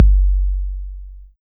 KICK175.wav